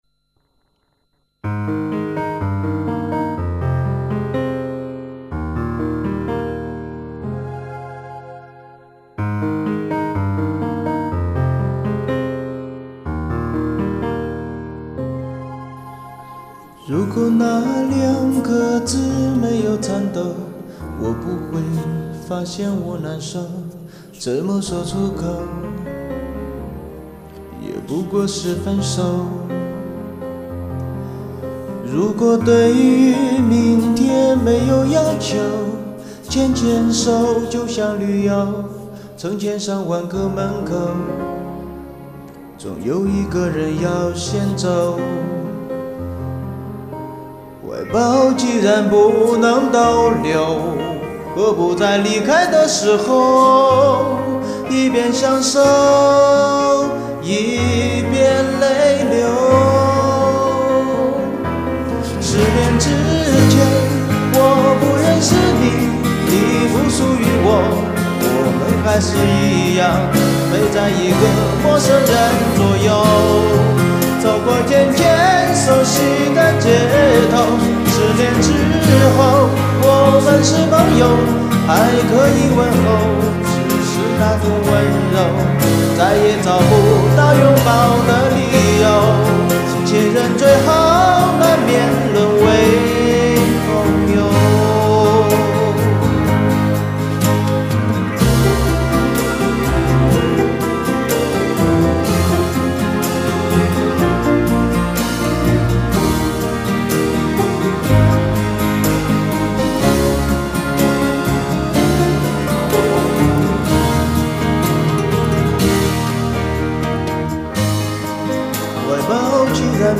这时本人第二次翻唱,唱的不好..呵呵,还请各位多提宝贵意见....谢谢!